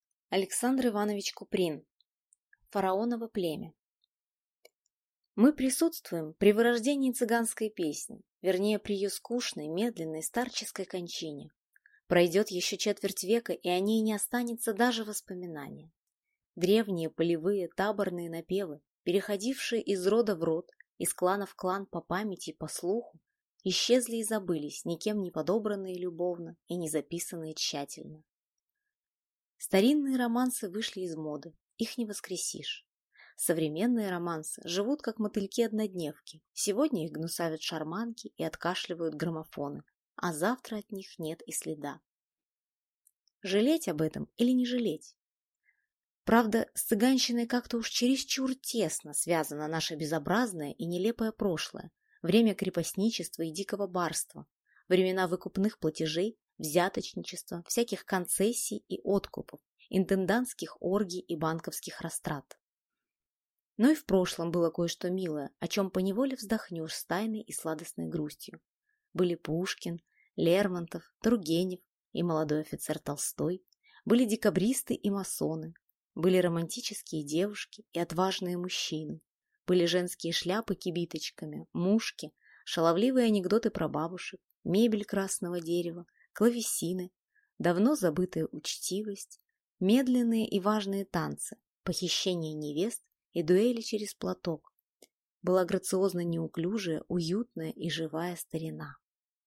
Аудиокнига Фараоново племя | Библиотека аудиокниг